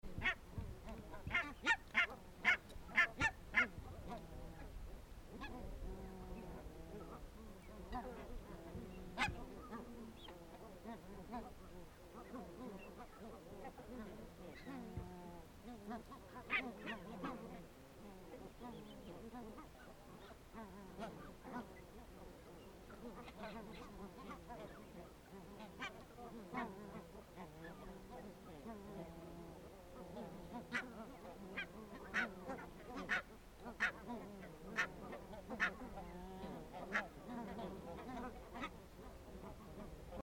Tai siltä se ainakin tuntui, kun seurasin ja kuuntelin noin kymmenen metrin päässä hitaasti laiduntavaa hanhijoukkoa. Hämmentävintä tilanteessa oli parvesta kuuluva lähes tauton vaimea yninä, jonka merkitys jäi minulle arvoitukseksi.
Parhaiten pääset itsekin tunnelmaan katsomalla alla olevaa kuvaa lähelläni ruokailevista valkoposkihanhista ja kuuntelemalla samalla niiden outoa ”laulantaa”.